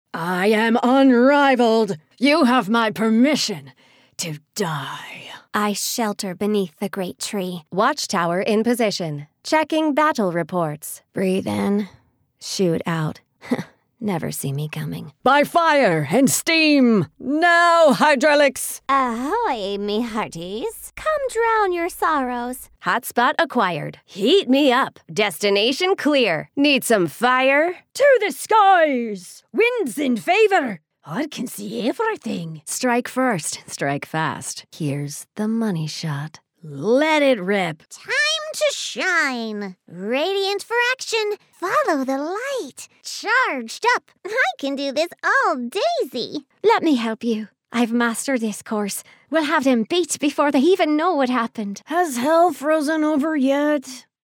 Here are some samples from Corporate Narration, Whiteboard, eLearning and Training modules, Educational and interpersonal learning modules.
My vocal range, acting chops and improv background create a wealth of dynamics to pull from in my performance.